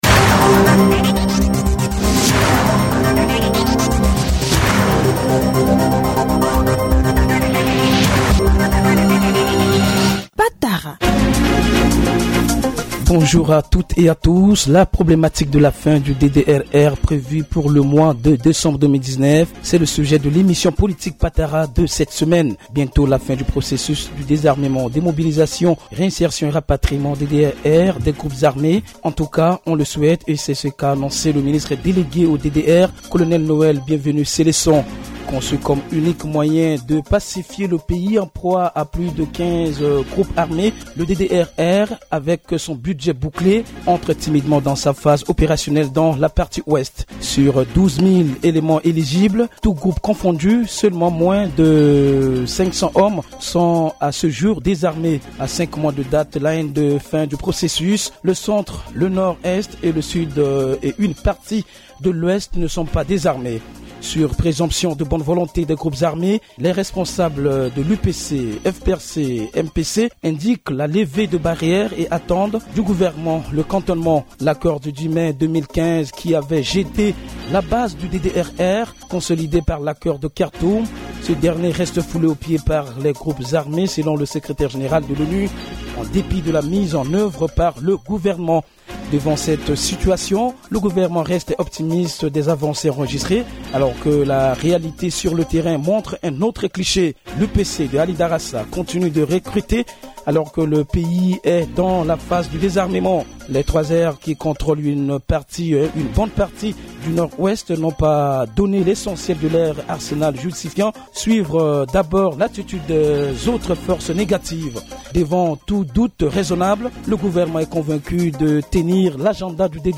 émission politique hebdomadaire